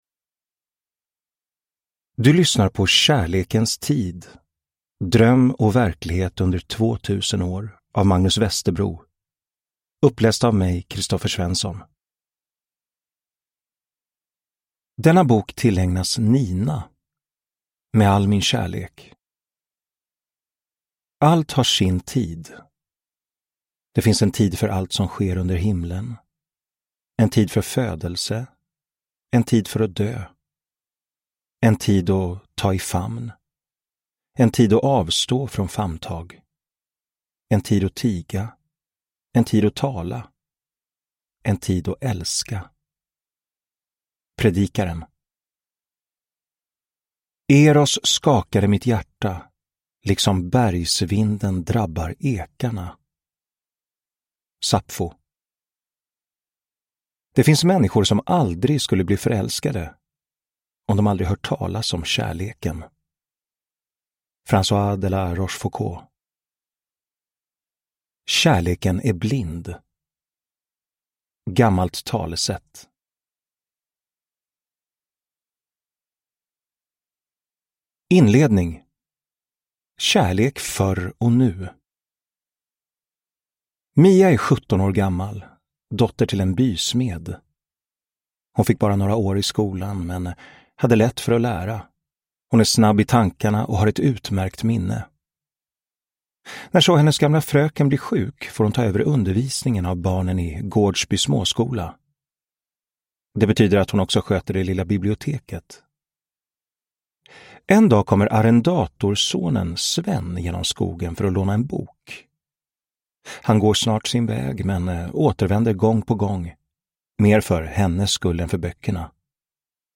Kärlekens tid : dröm och verklighet under två tusen år – Ljudbok